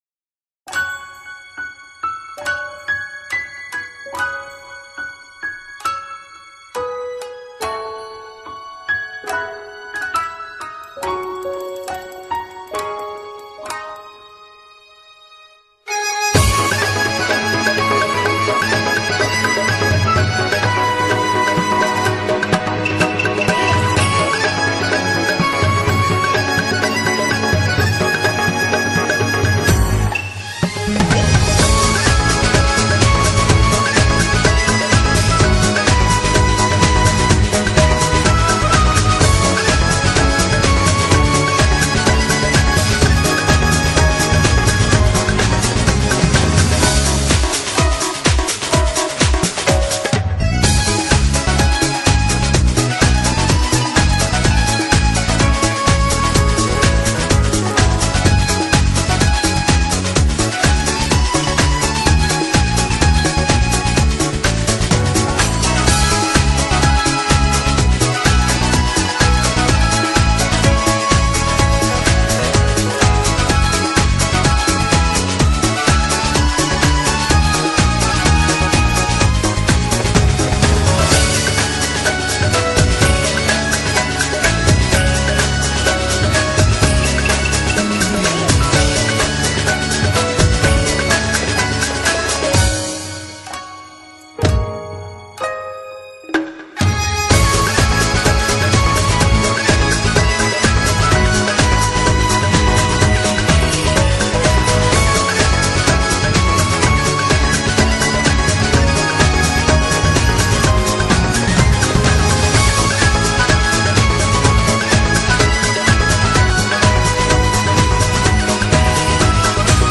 BPM70-125
Audio QualityPerfect (Low Quality)